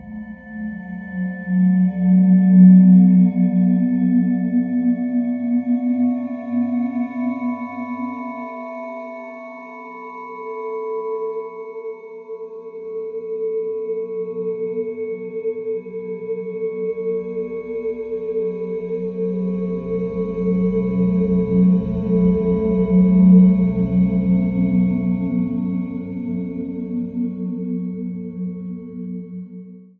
Tonos gratis para tu telefono – NUEVOS EFECTOS DE SONIDO DE AMBIENTE de DREAM02